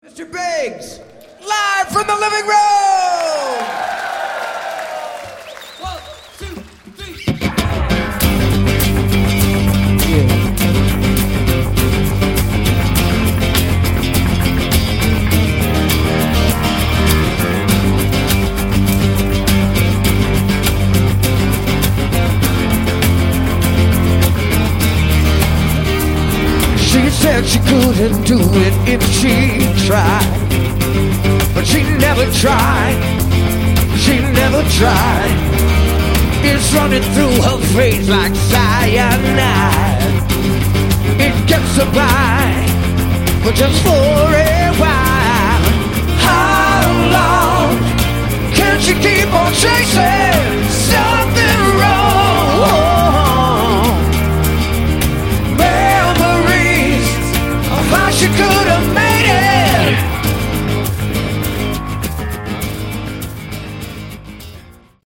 Category: Melodic Rock
lead vocals
drums, percussion, backing vocals
bass guitar, backing vocals
guitar, backing vocals